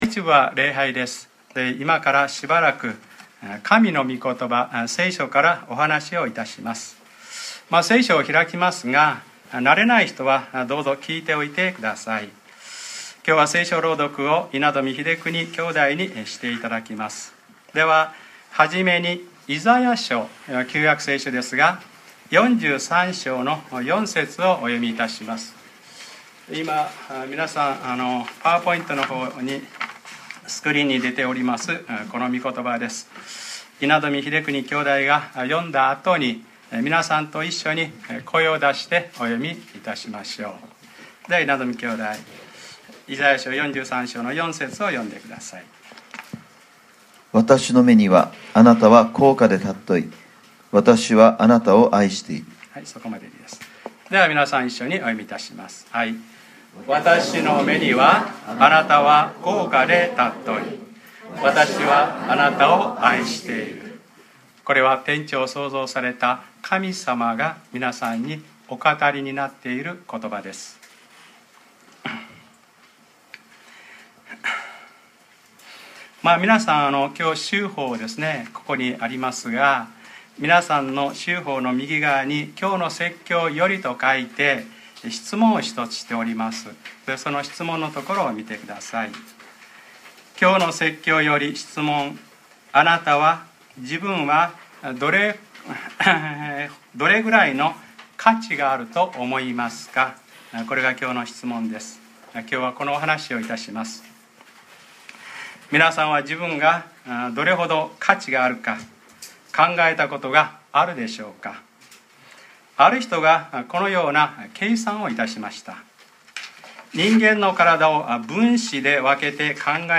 2013年6月09日(日）礼拝説教 『わたしの目にはあなたは高価で尊い』